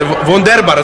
Worms speechbanks
brilliant.wav